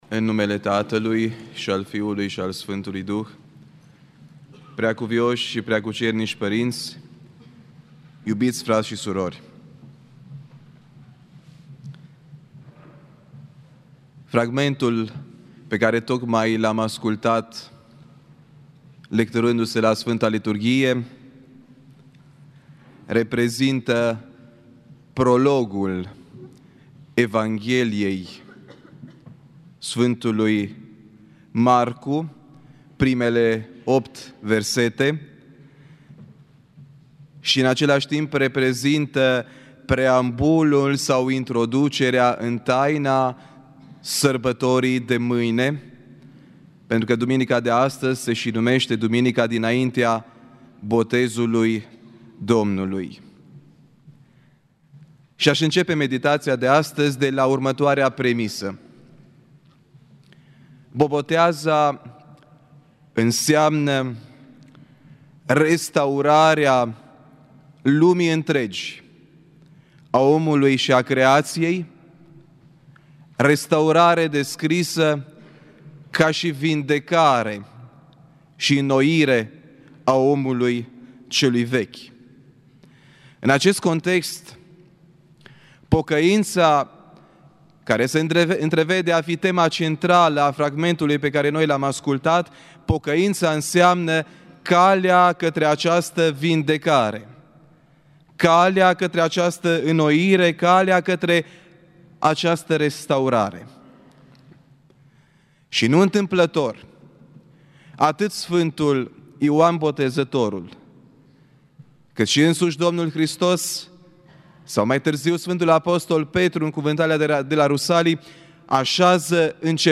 Predică la Duminica dinaintea Botezului Domnului
Cuvinte de învățătură